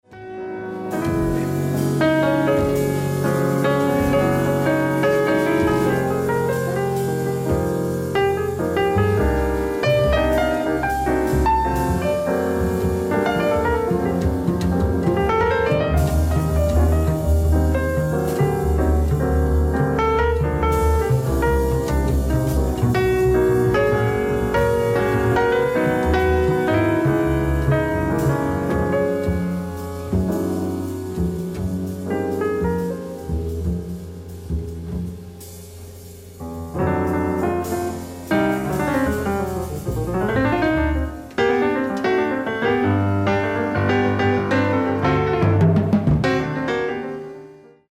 LIVE AT PARIS, FRANCE 06/09/1972